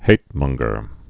(hātmŭnggər, -mŏng-)